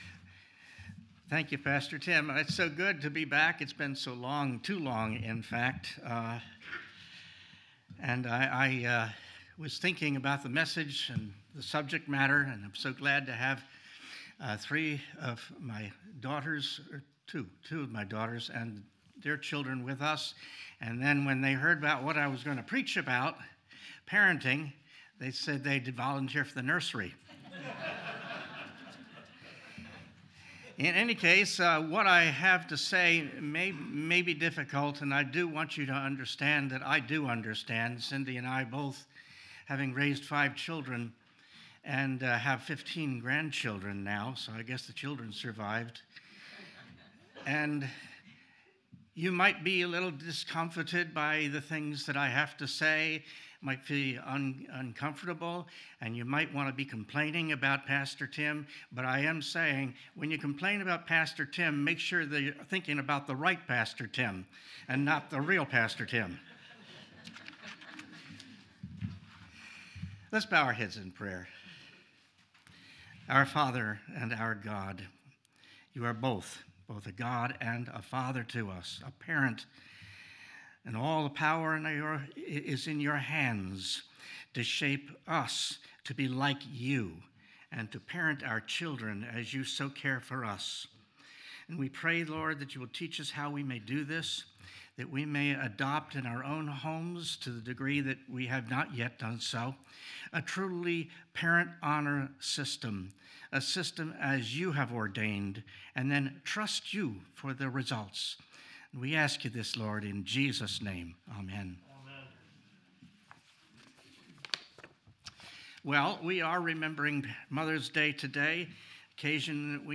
Guest Preachers